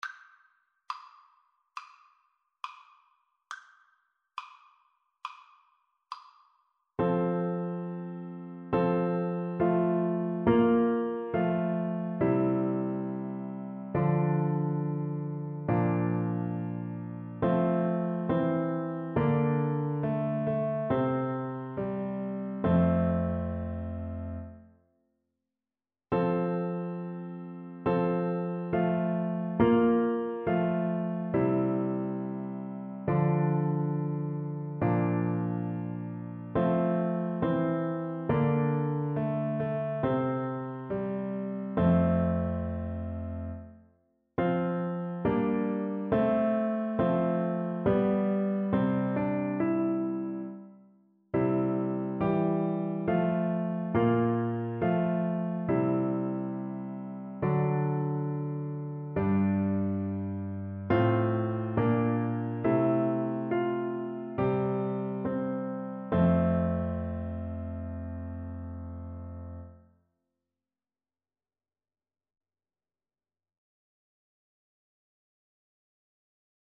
4/4 (View more 4/4 Music)
Classical (View more Classical Violin Music)